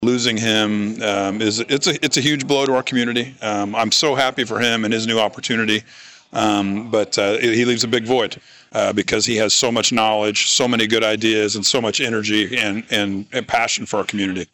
Colleagues, friends and community members all convened inside White Auditorium’s Little Theatre Wednesday to bid farewell to a longtime civil servant.